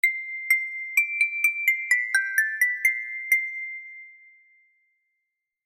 Kategorie Sms Töne